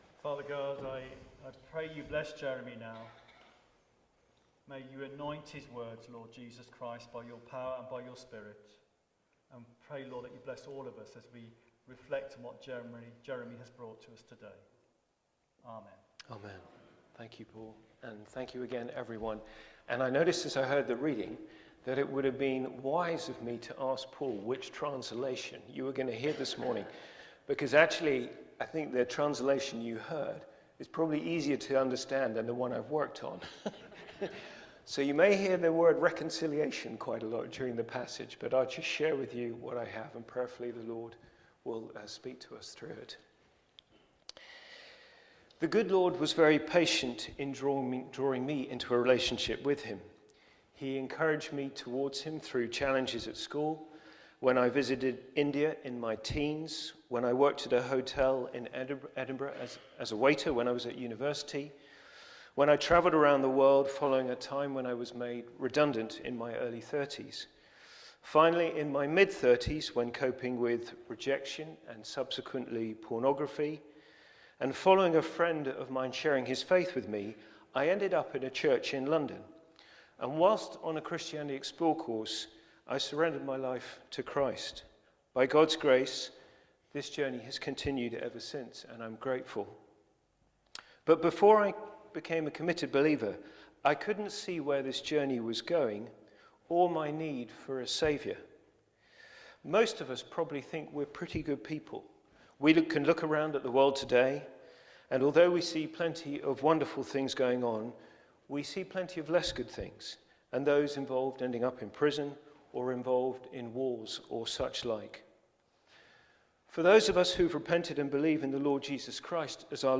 Media for Holy Communion on Sun 03rd Nov 2024 09:00 Speaker
Theme: God reconciled us to himself through Christ Sermon Search